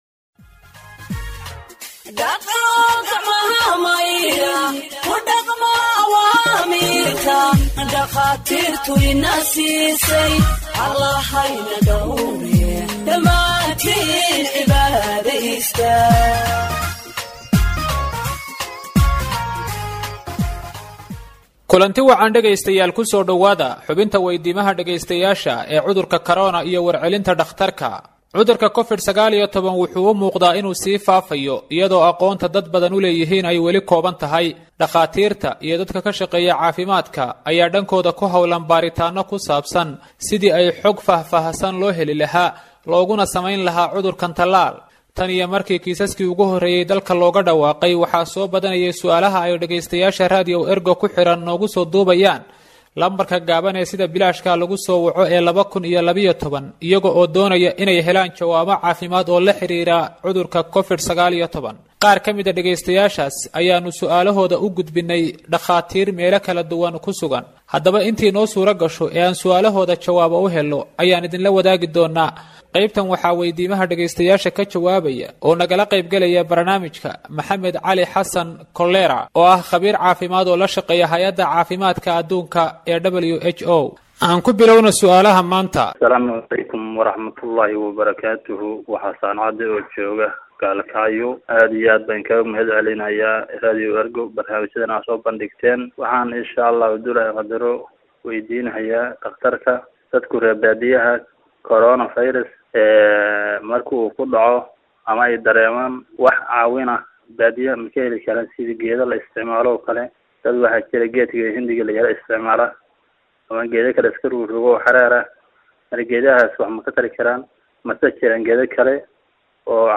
Health expert answers listeners’ questions on COVID 19 (24)